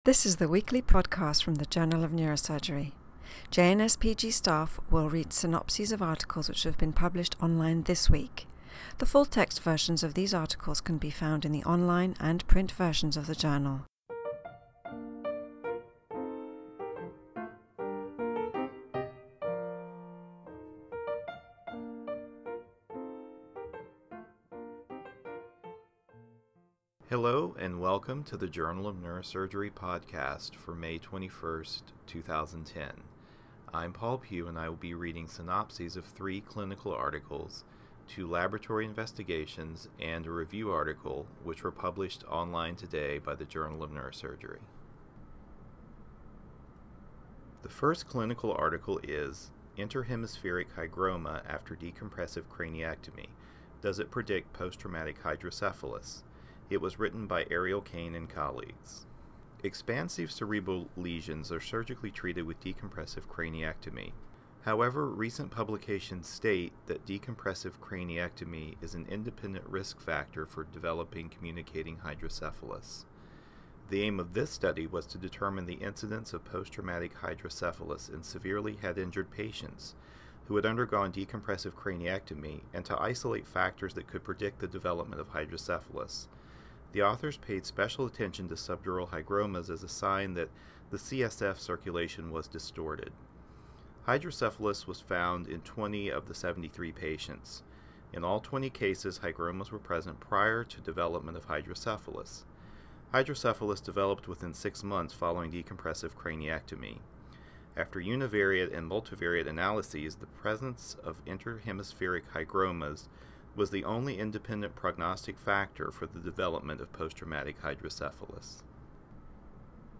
reads synopses of Journal of Neurosurgery articles published online on May 21, 2010.